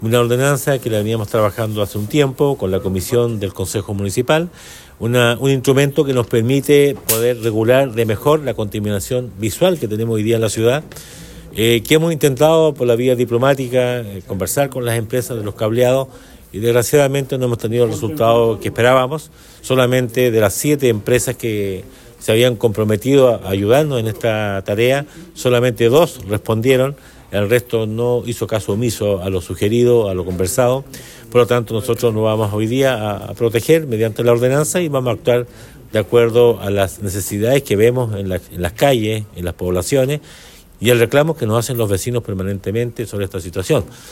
Según comentó el Alcalde Emeterio Carrillo, esta nueva ordenanza permitirá regular la contaminación visual que existe en la comuna, a pesar de que anteriormente se trató de dialogar con las empresas, lo que resultó infructuoso.